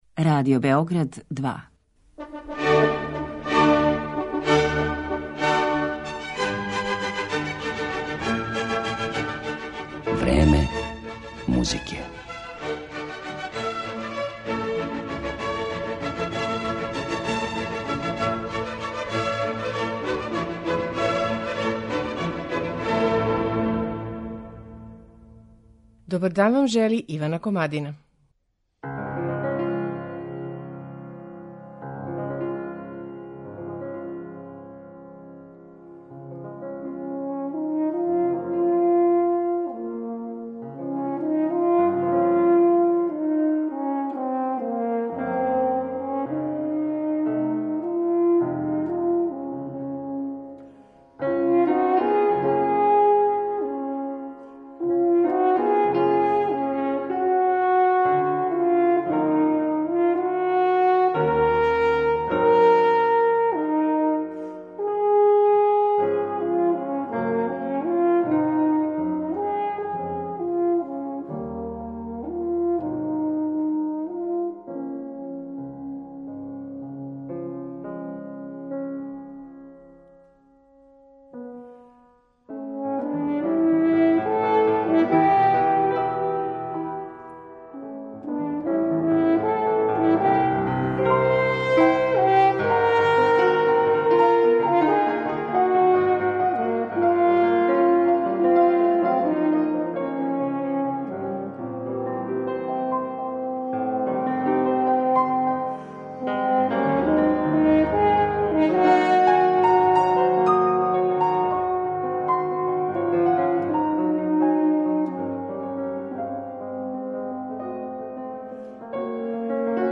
дела за хорну
четири различите хорне настале у 19. веку
четири клавира из тог доба.